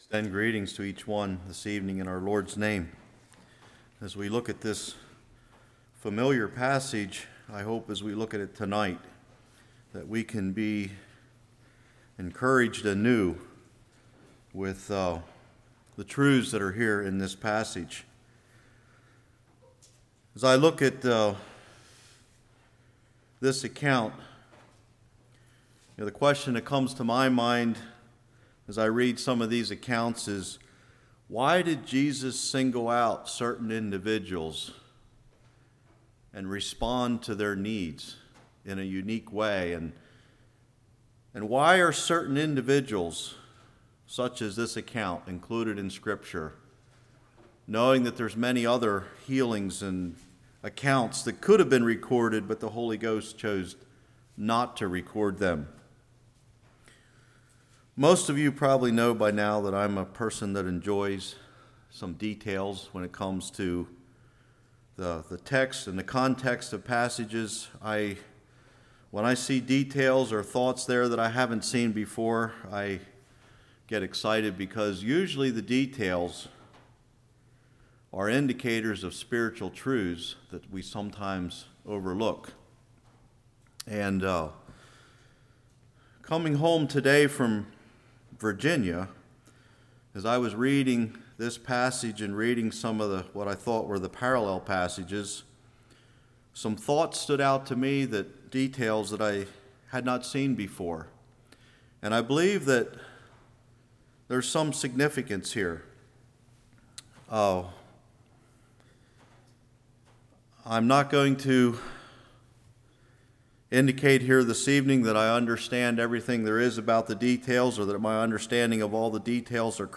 Luke 18:35-43 Service Type: Evening Compare Mark